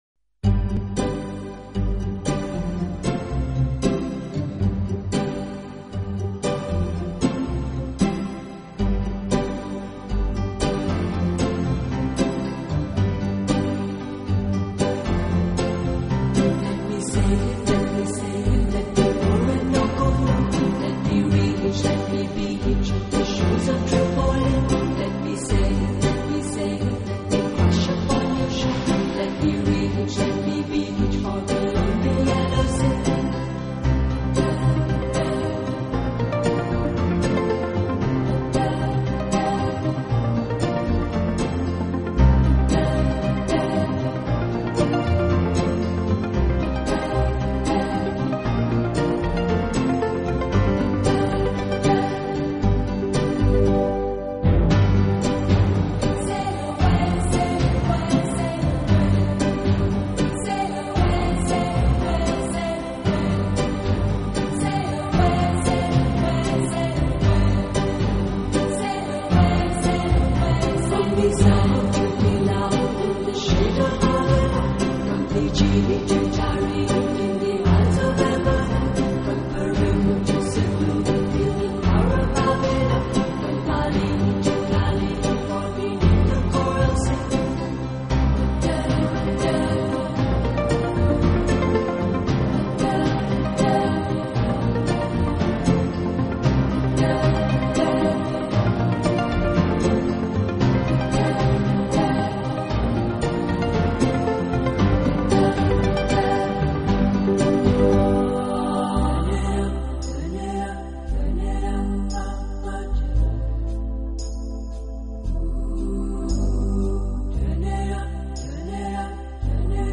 稠密的纹理，缥缈的语音，梦幻般迷人。